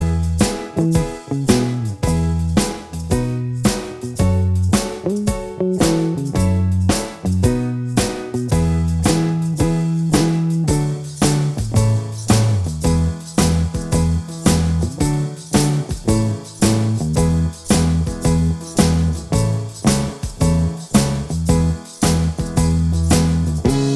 Minus Guitars Pop (1960s) 2:44 Buy £1.50